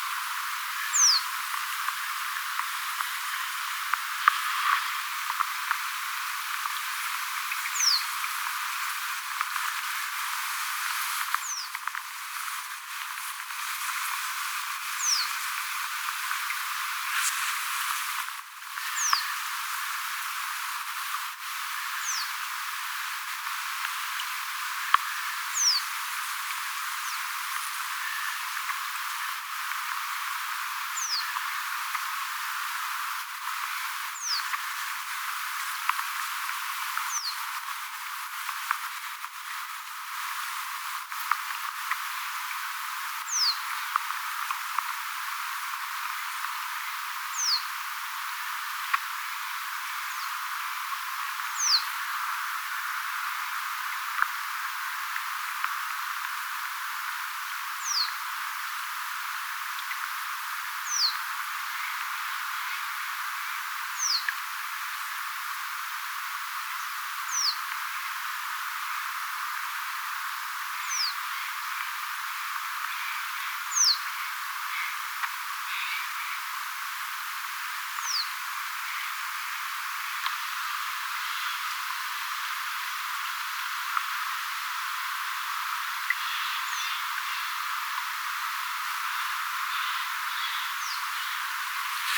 pajusirkun ääntelyä
pajusirkkulinnun_aantelya.mp3